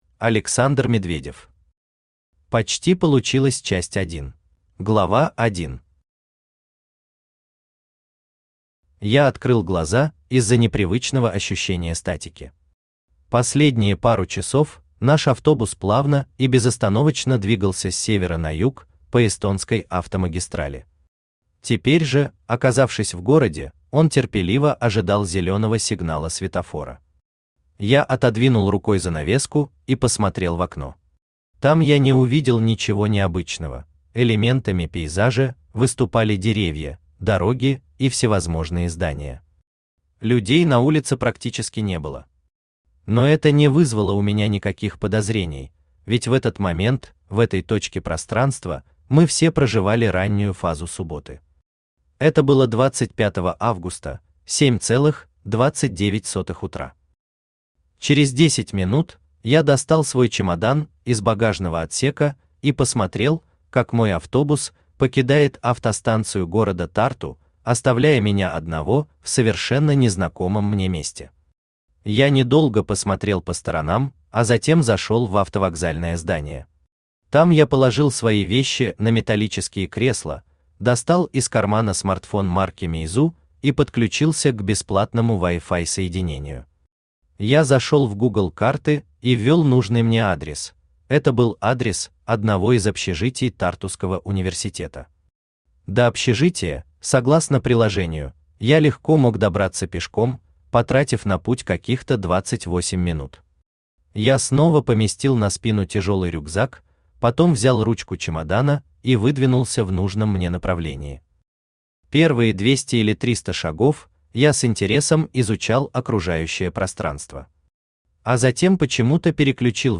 Аудиокнига Почти Получилось Vol.1 | Библиотека аудиокниг
Aудиокнига Почти Получилось Vol.1 Автор Александр Медведев Читает аудиокнигу Авточтец ЛитРес.